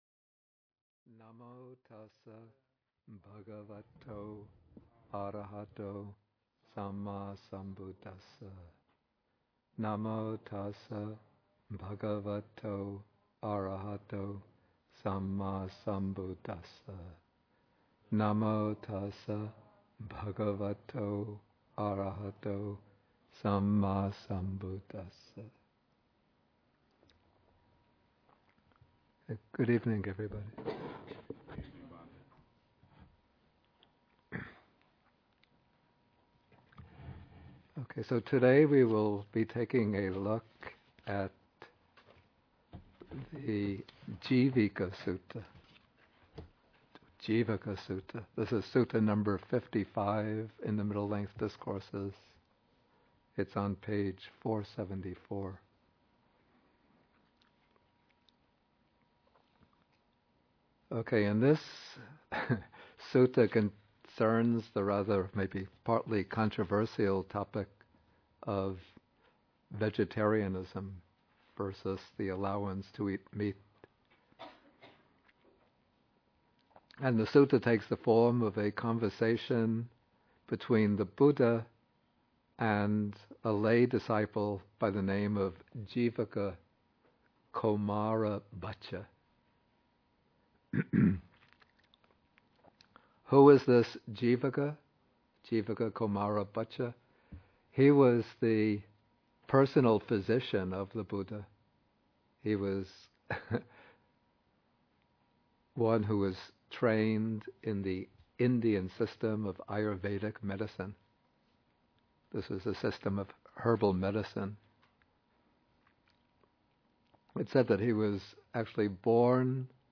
MN55, Bhikkhu Bodhi at Bodhi Monastery (lecture 139)